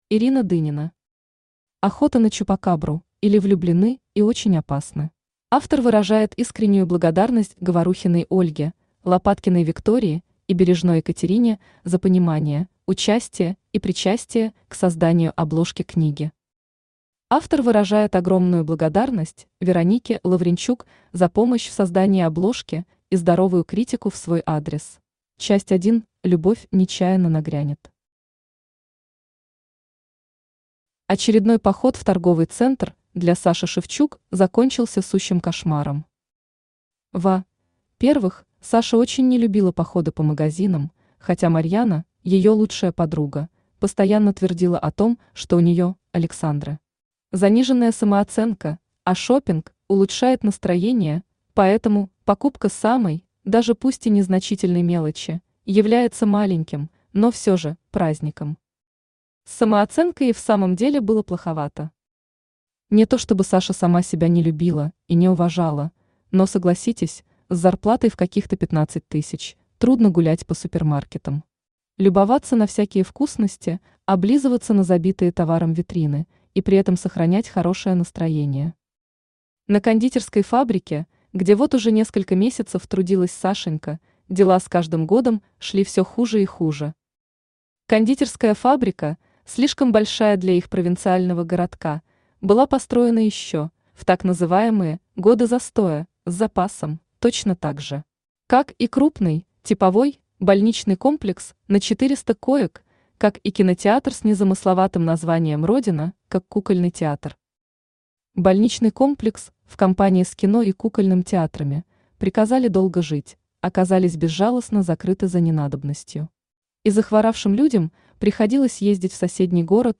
Аудиокнига Охота на чупакабру, или влюблены и очень опасны | Библиотека аудиокниг
Читает аудиокнигу Авточтец ЛитРес.